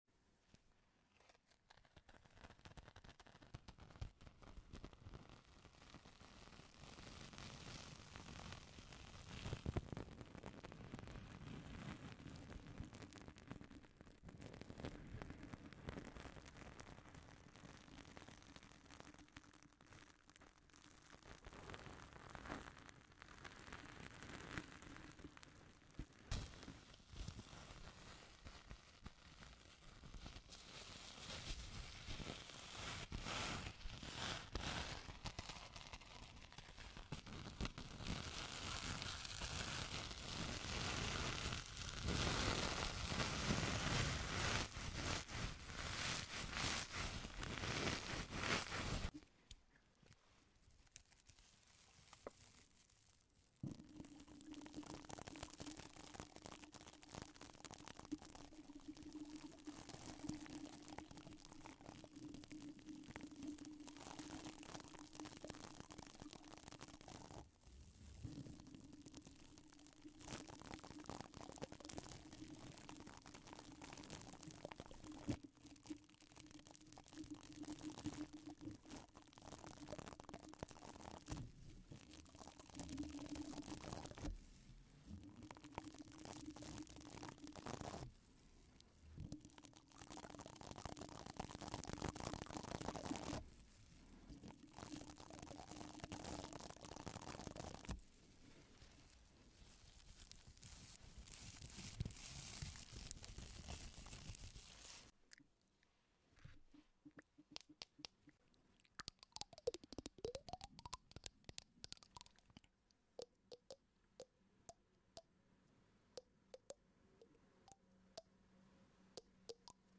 Tapp Tapp Sound From Objects Sound Effects Free Download